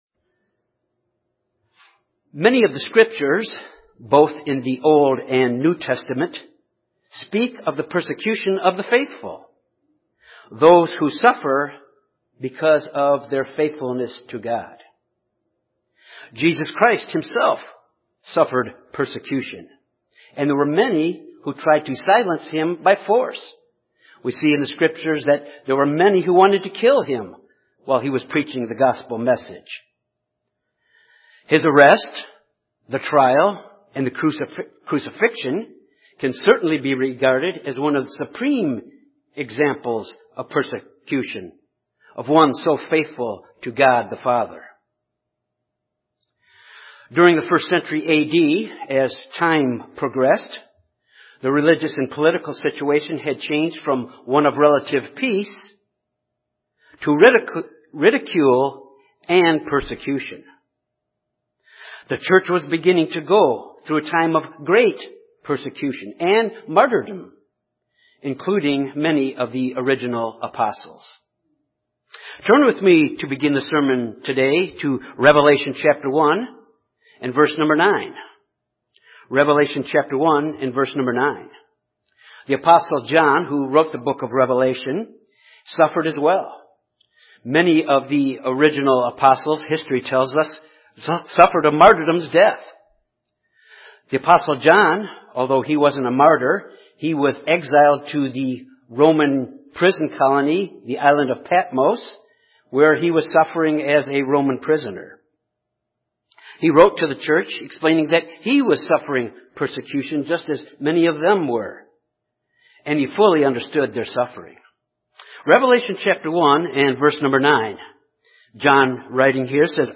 This message examines how we must learn the enduring patience we need to finish our quest for the Kingdom of God by means of the help of God’s Holy Spirit.